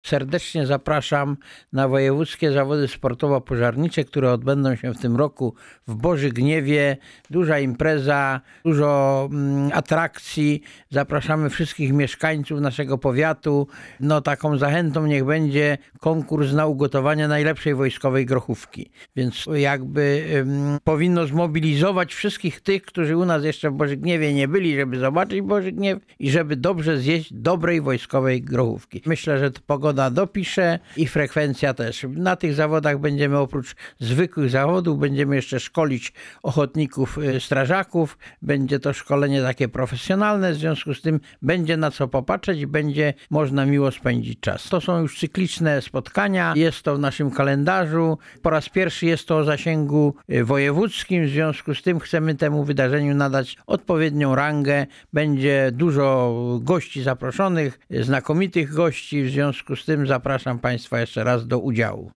Mówi Wiesław Zając – członek Zarządu Powiatu Wrocławskiego.